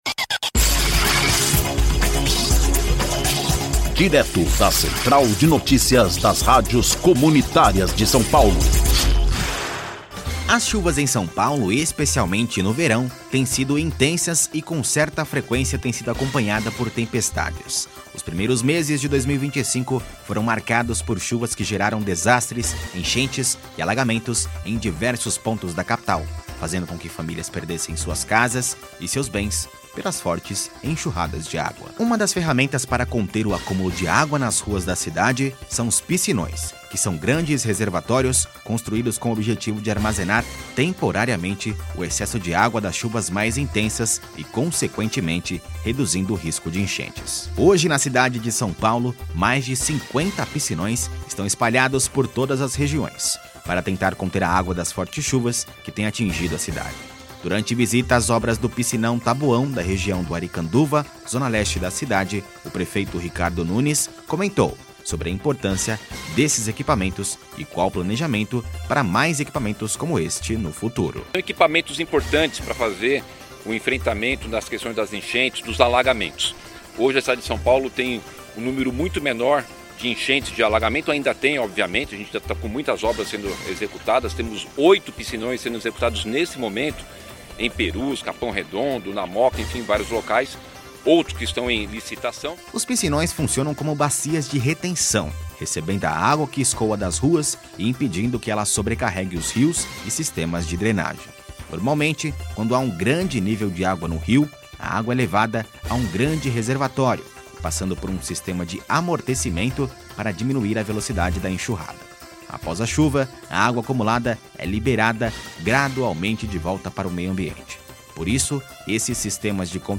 Durante visita às obras do piscinão Taboão, da região da Aricanduva, na zona leste da cidade, o Prefeito Ricardo Nunes comentou sobre a importância destes equipamentos, e qual o planejamento para mais equipamentos como este no futuro.